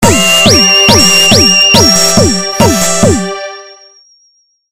In questo boxxetto qua di fianco.. troveremo dei loopz fatti da me con un programmino spettacolare... che si chiama Fruity Loops versione 3.4